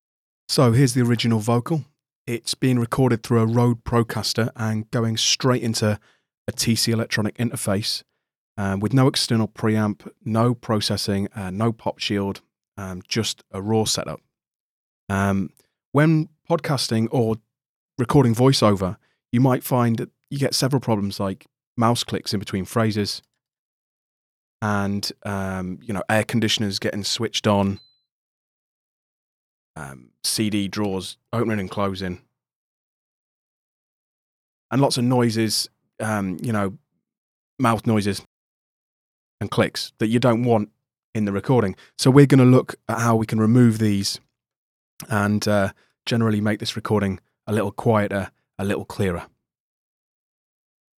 Due to the fact I used a dynamic microphone the recording was in need of a little ‘air’, so using the same EQ I used a high shelf filter to boost the highs.
The equlaised vocal.